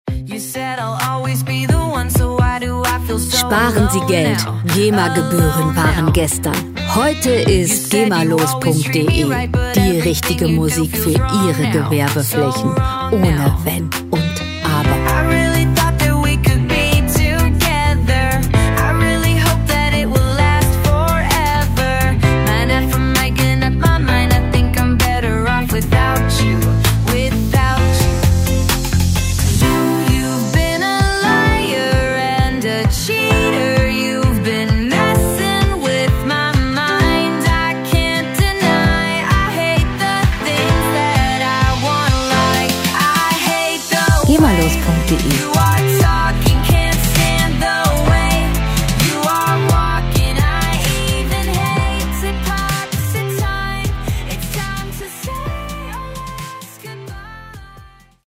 gemafreie Pop Musik
Musikstil: UK Pop
Tempo: 130 bpm
Tonart: C-Dur
Charakter: up-to-date, jugendlich
Instrumentierung: Popsängerin, E-Gitarre, Synthesizer